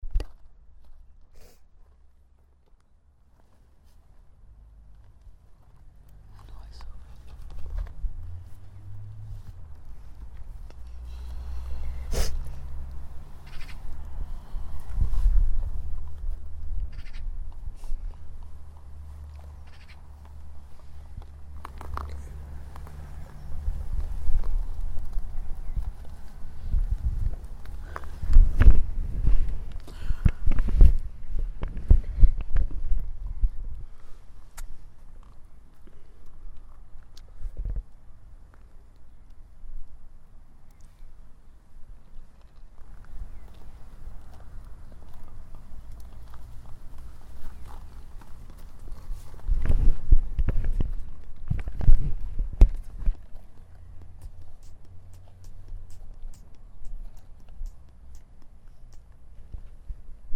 cours collège Darnet
vent, camion